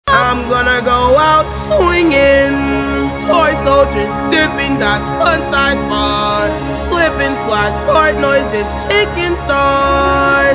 chicken stars Meme Sound Effect